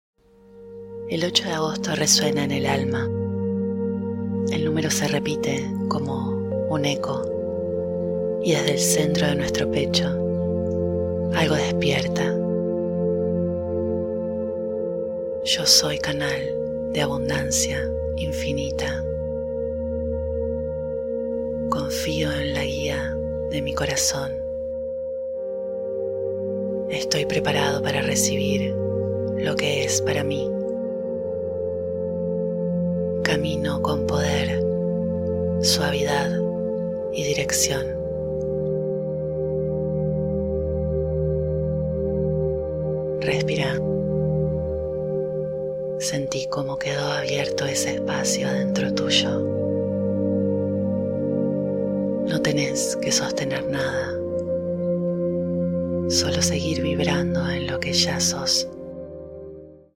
Meditacion Portal 8 8 2025 – Activación con frecuencia 528 Hz - Abundancia infinita